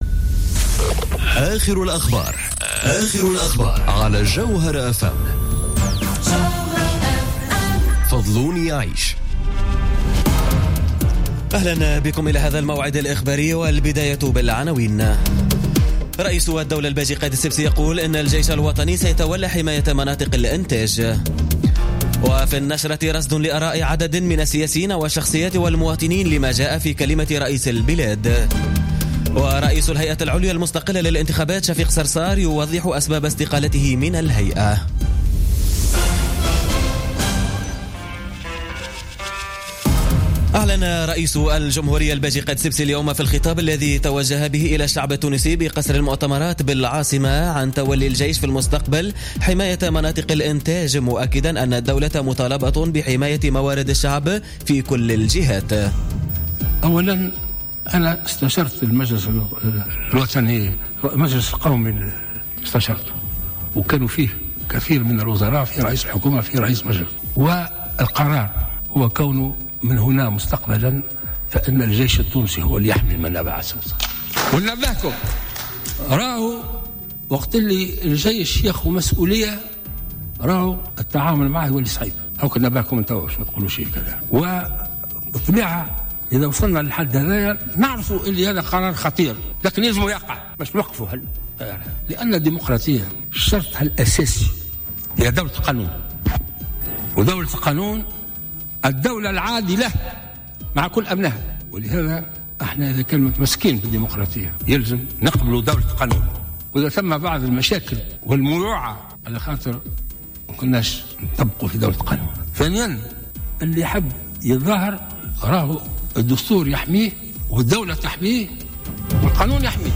نشرة أخبار السابعة مساء ليوم الأربعاء 10 ماي 2017